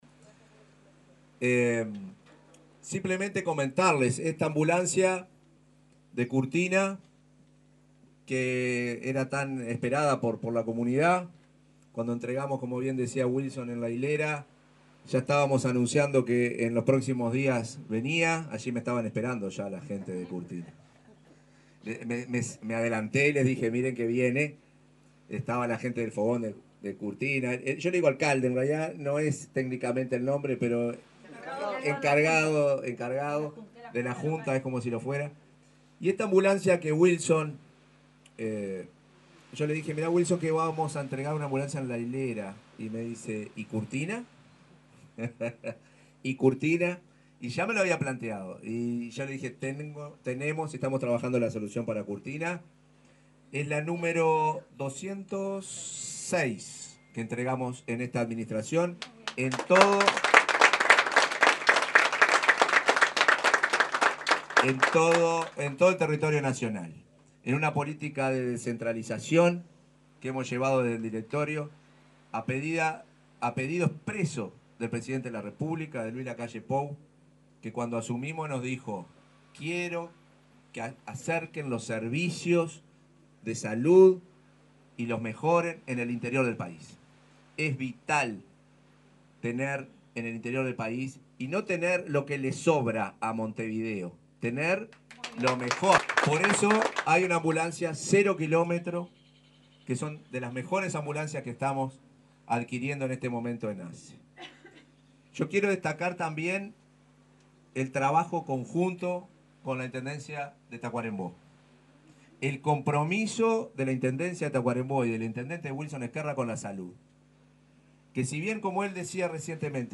Palabras del presidente de ASSE, Marcelo Sosa, en la policlínica de Curtina
Este miércoles 2, la Administración de los Servicios de Salud del Estado (ASSE) entregó una ambulancia para la policlínica de Curtina, en Tacuarembó.